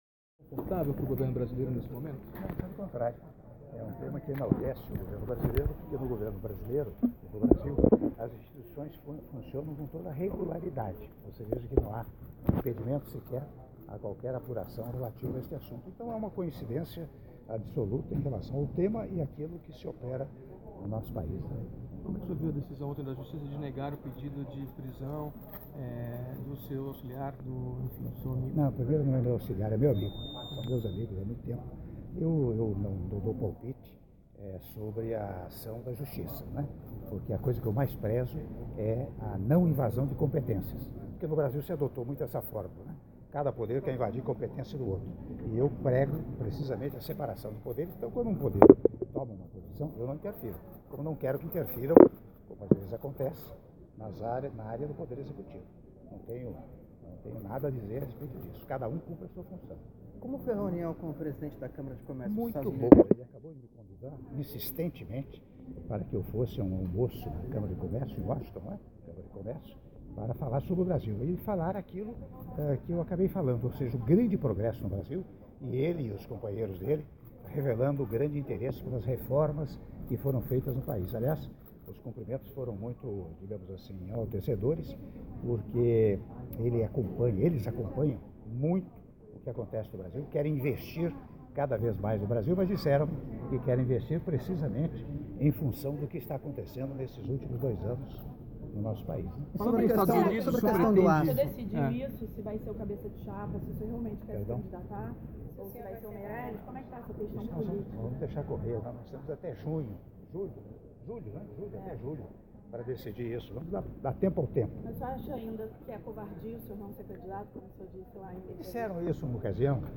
Áudio da entrevista coletiva concedida pelo Presidente da República, Michel Temer